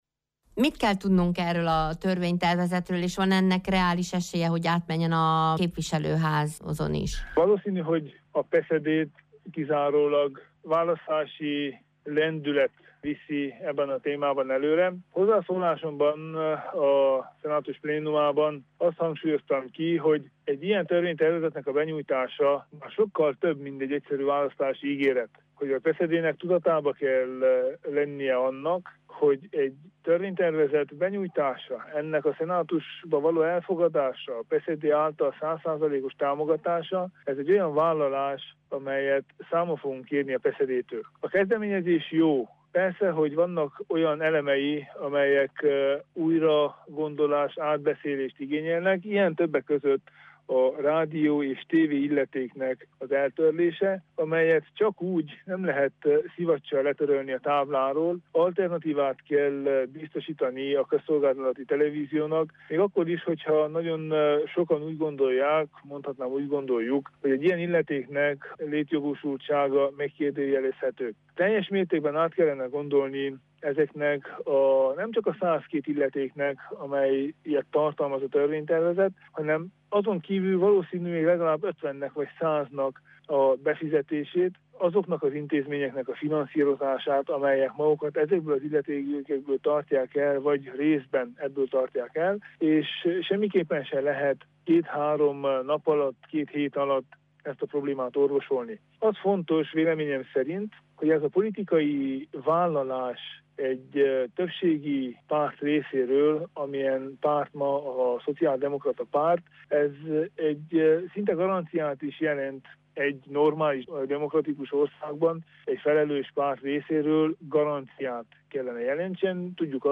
Táncos Barna szenátort hallják.